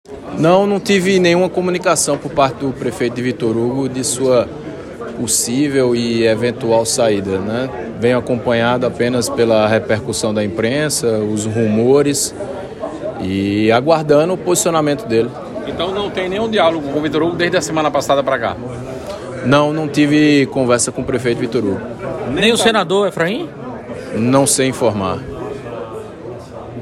Abaixo a fala do deputado George Morais.